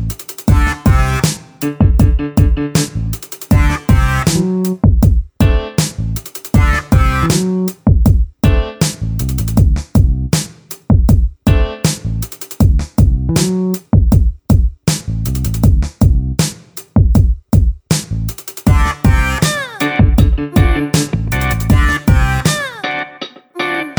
no Backing Vocals Reggae 4:07 Buy £1.50